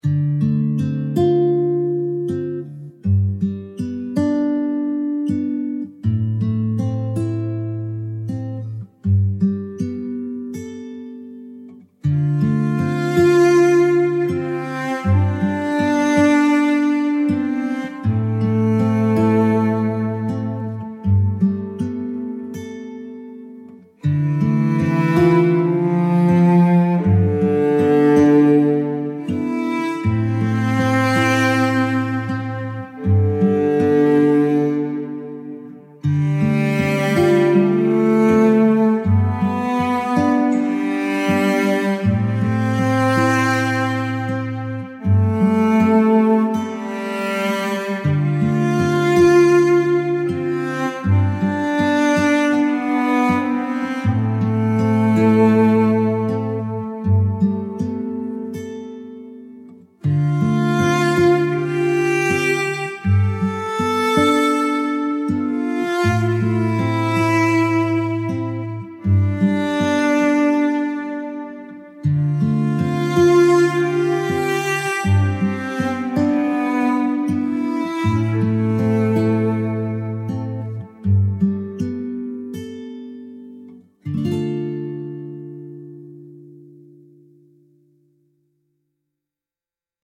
timeless acoustic melody with fingerpicked guitar and soft cello